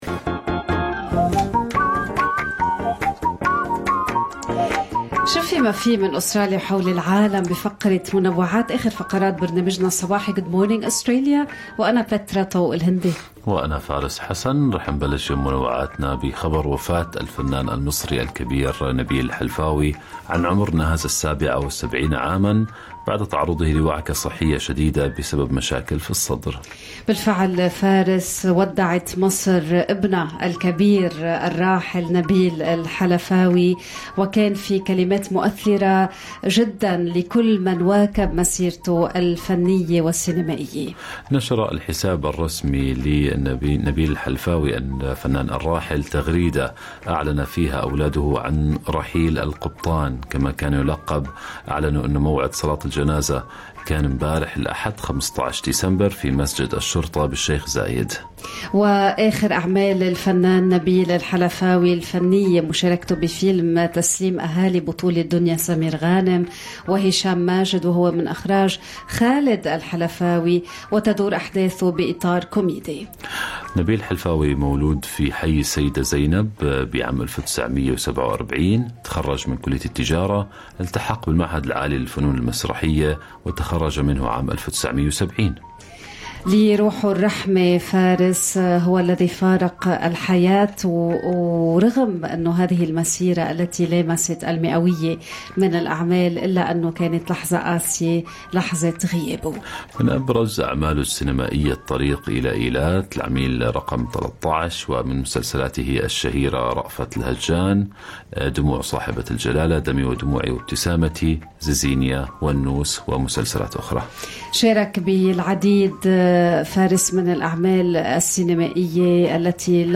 نقدم لكم فقرة المنوعات من برنامج Good Morning Australia التي تحمل إليكم بعض الأخبار والمواضيع الأكثر رواجا على مواقع التواصل الاجتماعي.